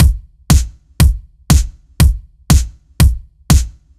Index of /musicradar/french-house-chillout-samples/120bpm/Beats
FHC_BeatD_120-02_KickSnare.wav